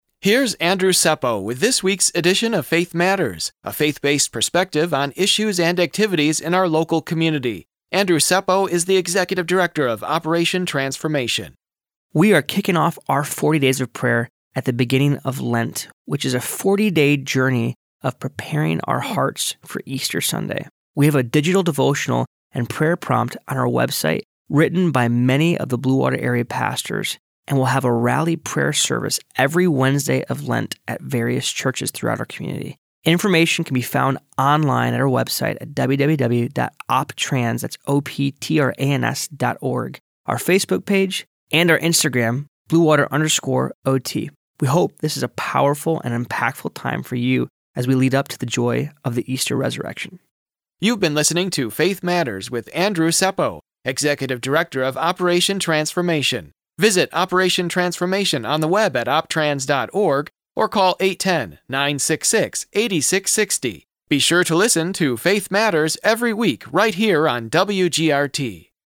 Faith Matters is a weekly radio feature that airs every Monday on WGRT 102.3 FM. Featuring information and commentary about spiritual issues - nationally and in your local community.